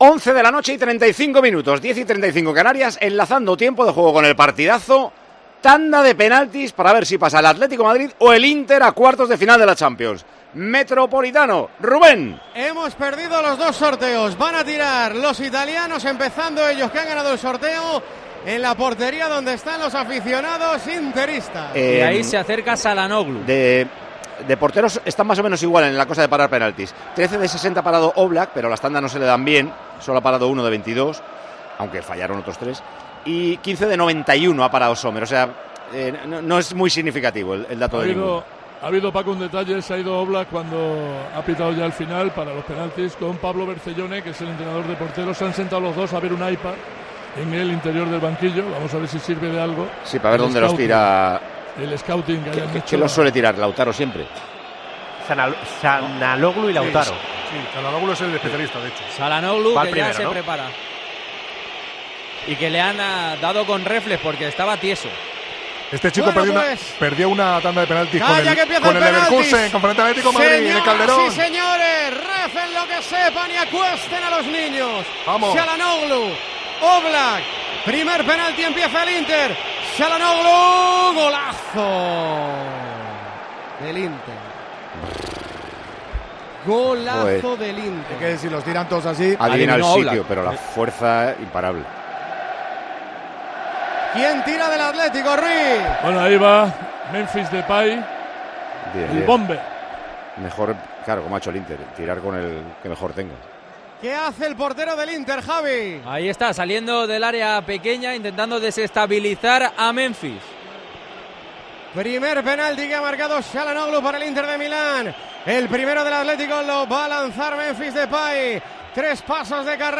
Escucha la tanda de penaltis, narrada por